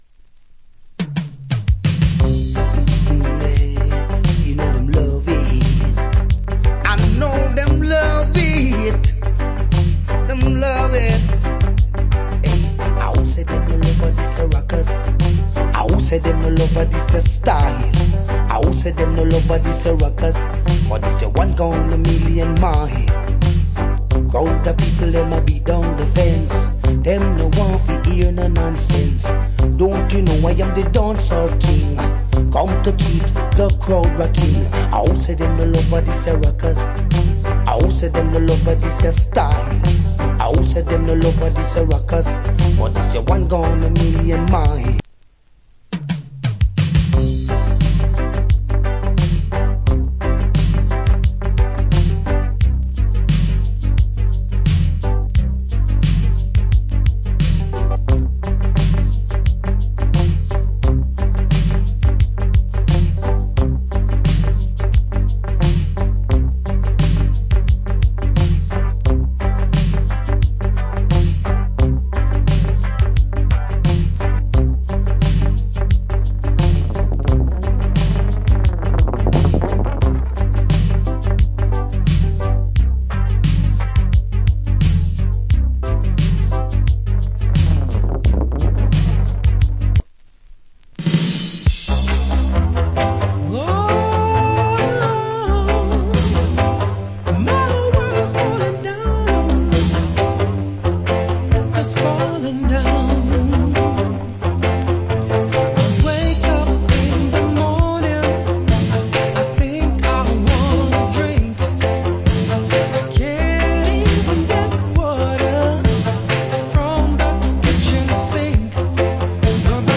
80's Good Reggae Vocal.